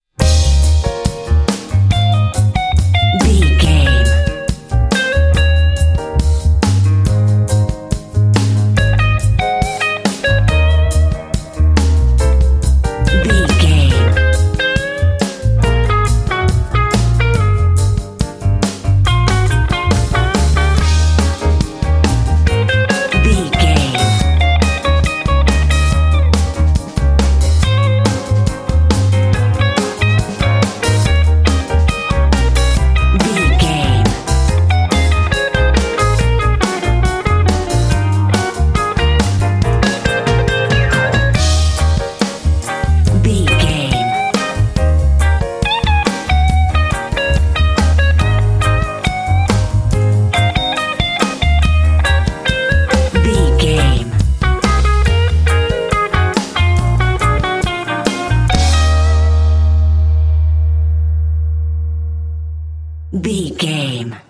Aeolian/Minor
E♭
funky
groovy
bright
piano
drums
electric guitar
bass guitar
blues
jazz